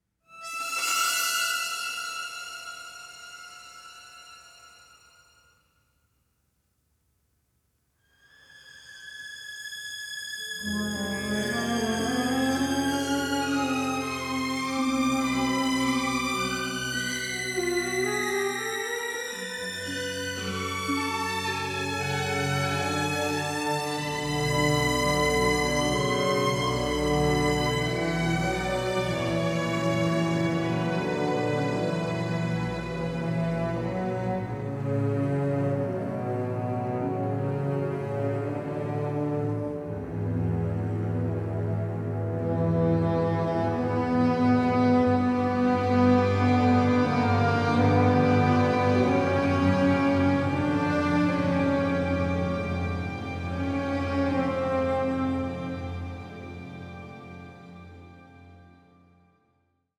noir score
record the music in Paris